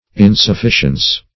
\In`suf*fi"cience\